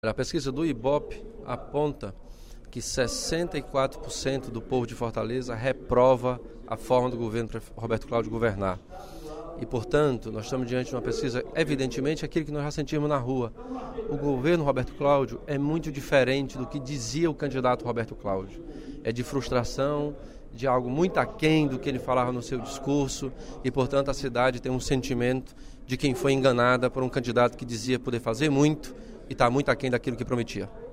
O deputado Elmano Freitas (PT) avaliou, durante o primeiro expediente da sessão plenária da Assembleia Legislativa desta terça-feira (22/09), a pesquisa do Ibope em que o prefeito Roberto Cláudio aparece com 64% de reprovação.